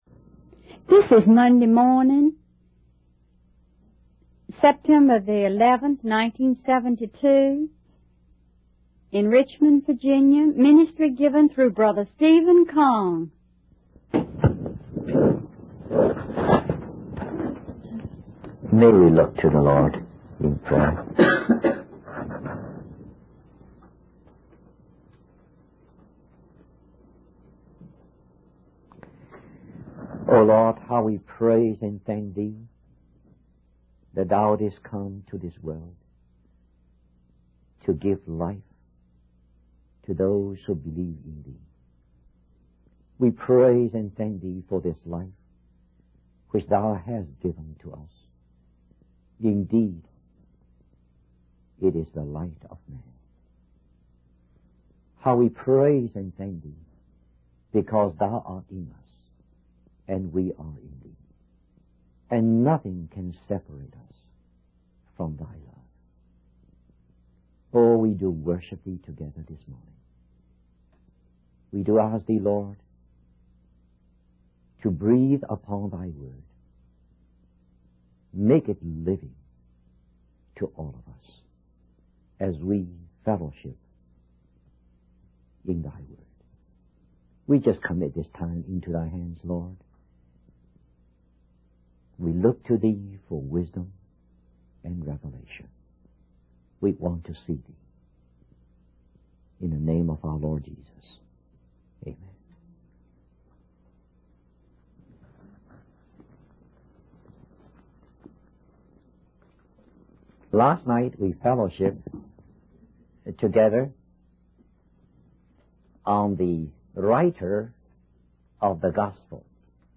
In this sermon, the preacher focuses on the Gospel of John and its four different approaches to presenting Jesus. He uses the symbolic pictures of the four living creatures before the throne of God in the book of Revelation to help us remember these approaches.